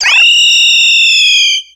Cri d'Étouraptor dans Pokémon X et Y.